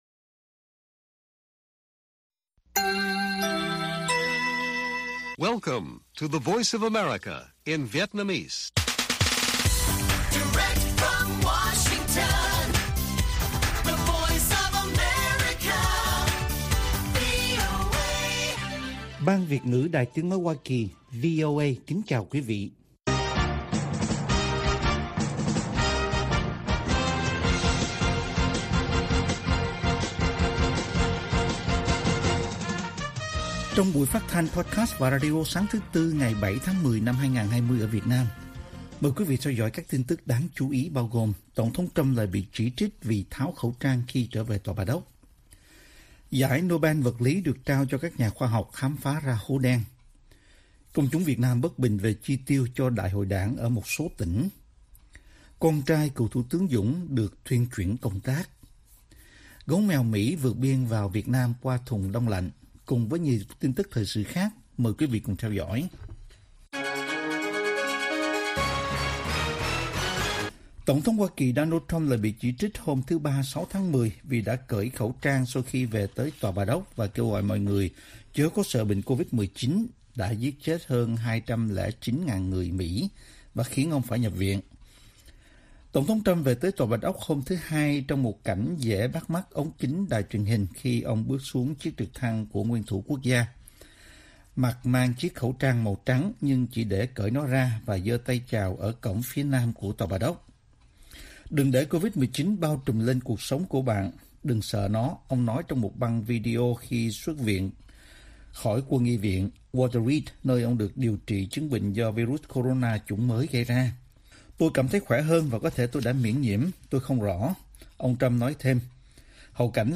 Bản tin VOA ngày 7/10/2020